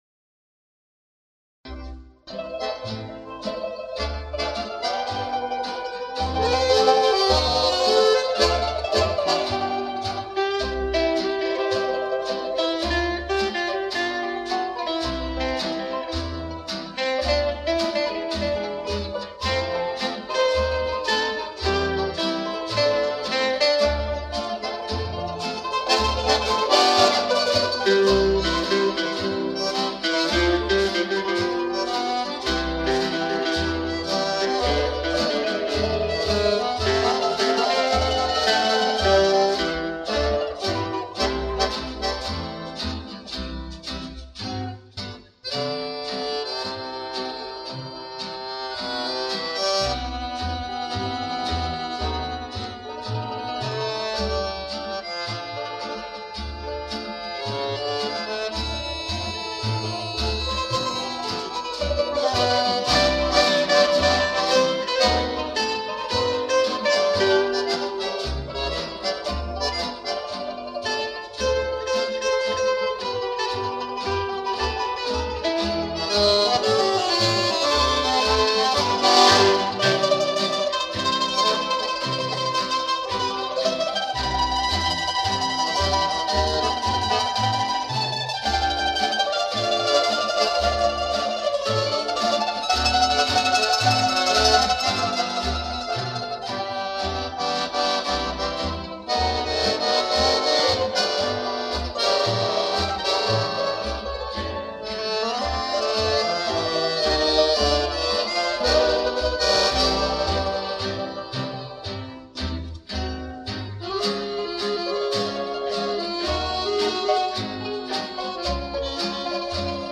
Звук нормализован но пикам, а не по средней громкости.